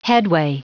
Prononciation du mot headway en anglais (fichier audio)
Prononciation du mot : headway